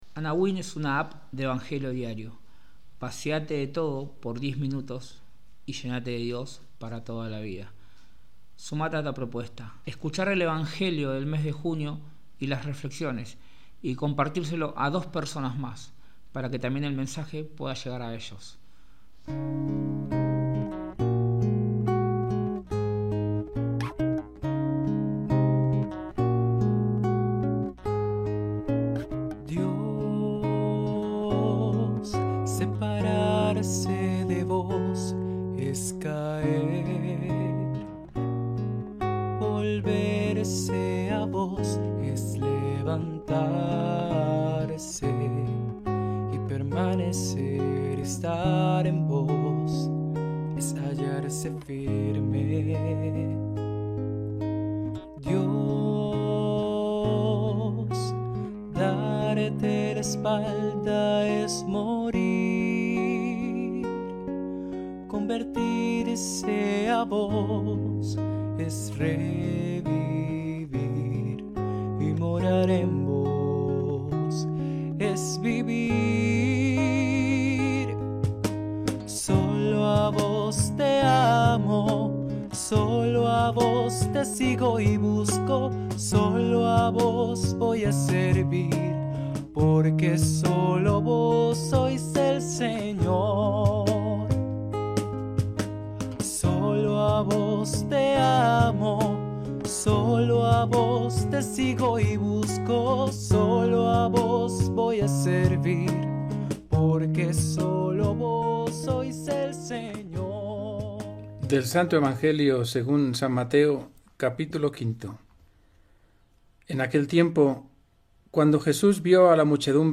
Reflexión
• Música